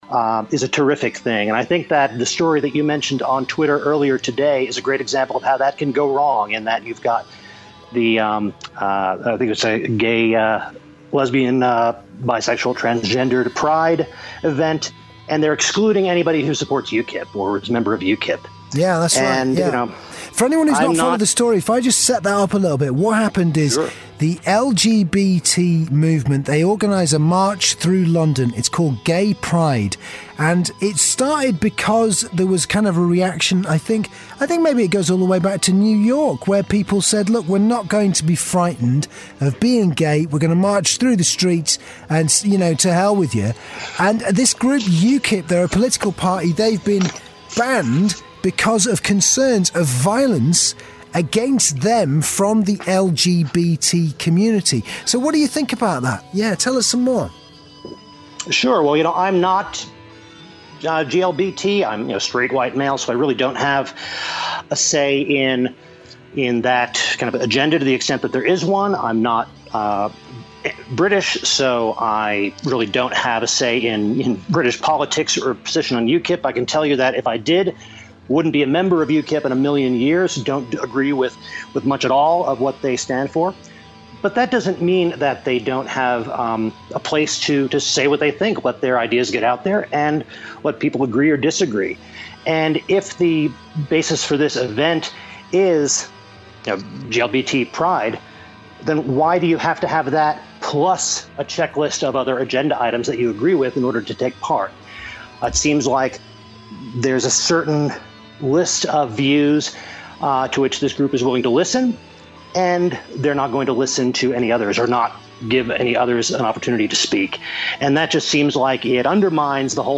Lefty violence, a caller on this week's podcast.